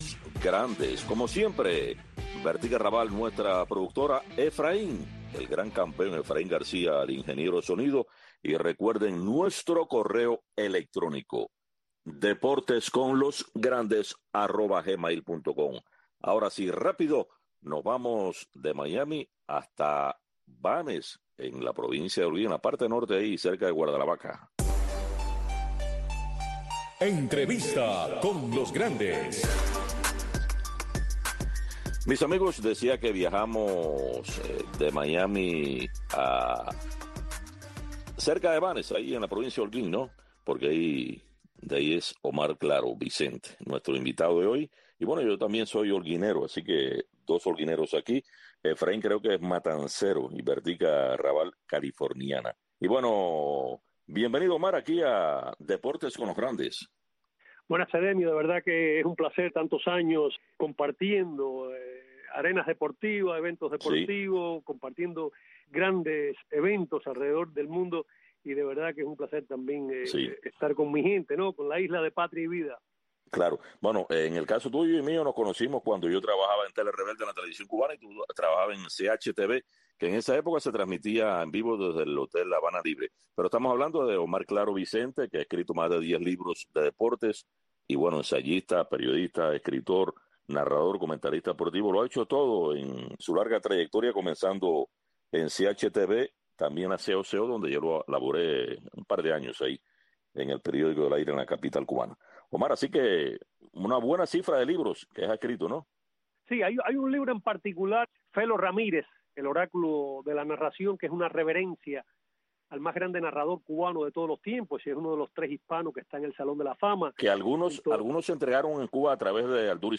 Deportes con los grandes. Un programa de Radio Marti, especializado en entrevistas, comentarios, análisis de los grandes del deporte.